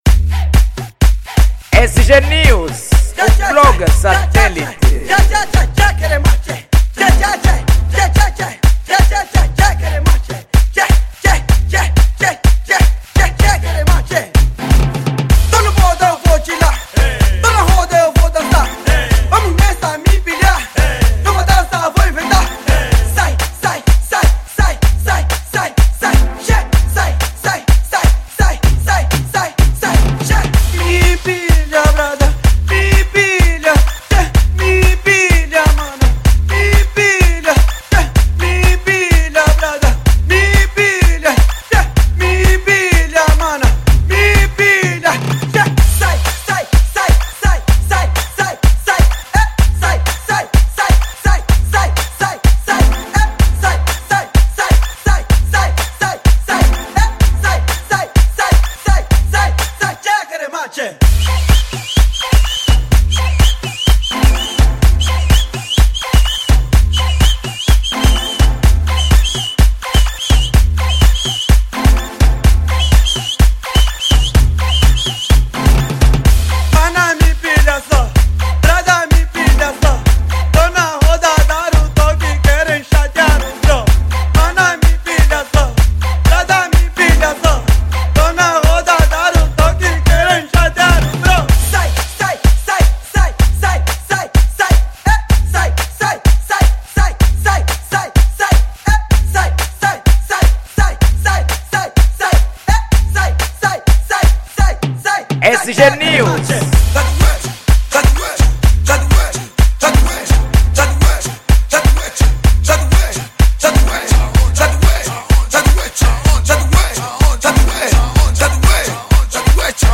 Género : Gqom